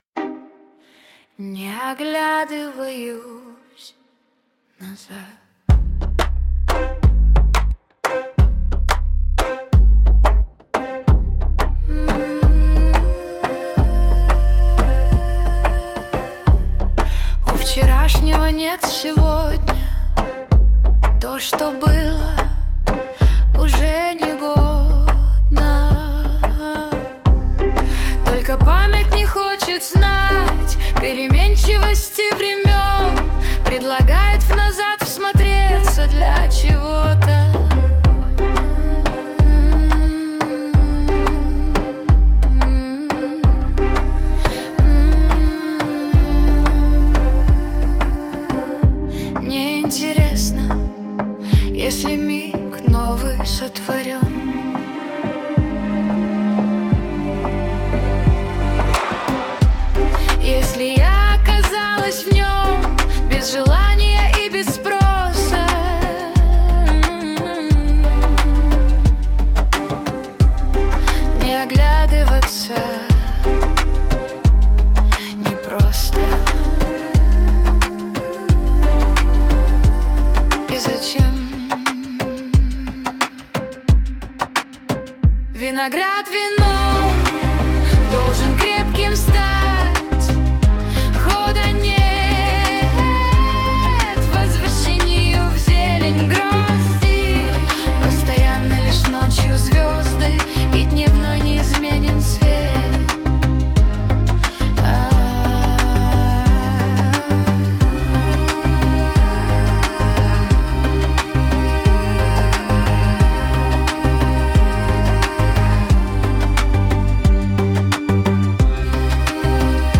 mp3,3137k] Авторская песня